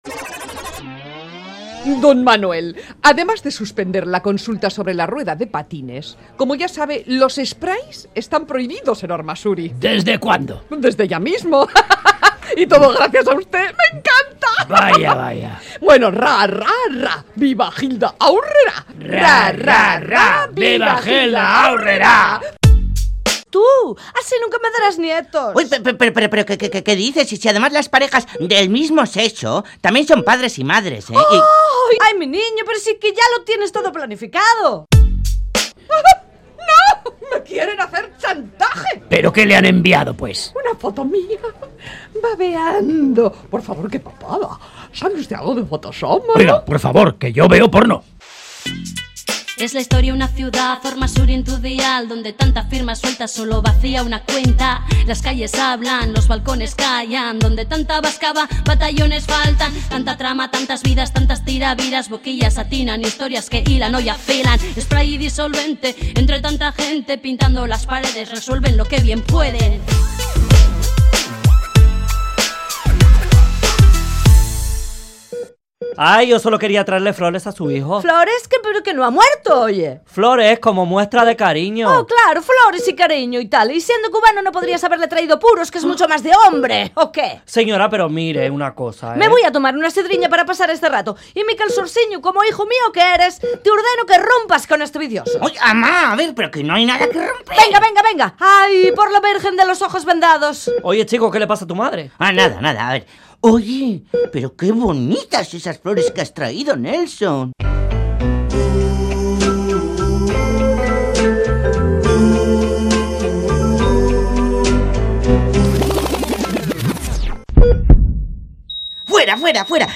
Entrega número 16 de la Radio-Ficción “Spray & Disolvente”